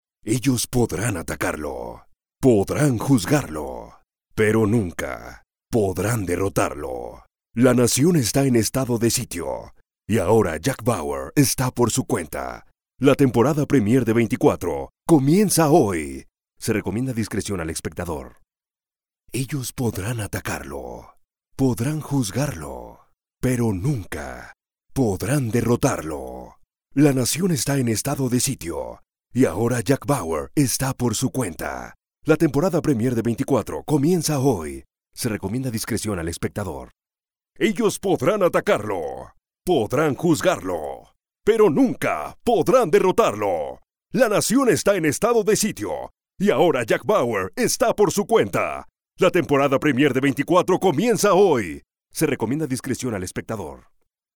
Movie Trailers
Styles: Warm, Elegant, Natural, Conversational, Sales Man, Corporate, Legal, Deep.
Equipment: Neumann TLM 103, Focusrite Scarlett, Aphex Channel, Source Connect
BaritoneBassDeepLowVery Low